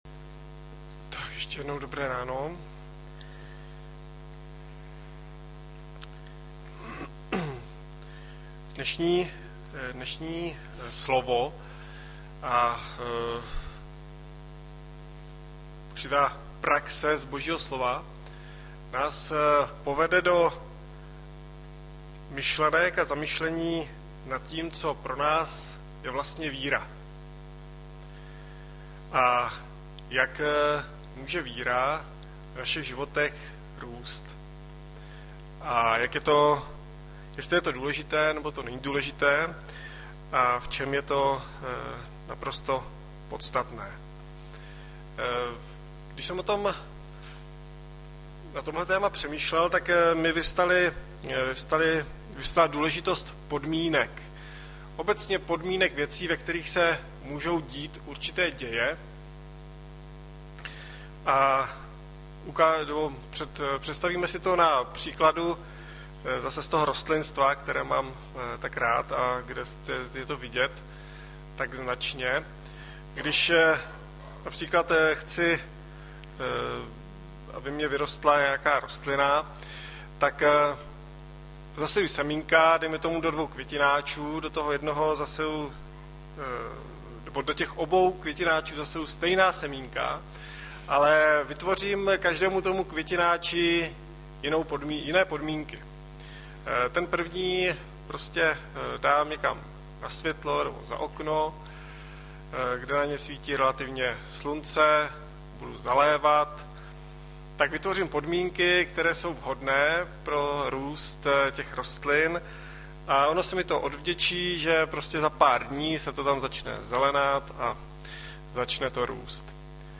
Hlavní nabídka Kázání Chvály Kalendář Knihovna Kontakt Pro přihlášené O nás Partneři Zpravodaj Přihlásit se Zavřít Jméno Heslo Pamatuj si mě  06.05.2012 - Čím je pro nás víra - Jan 20-19-29 Audiozáznam kázání si můžete také uložit do PC na tomto odkazu.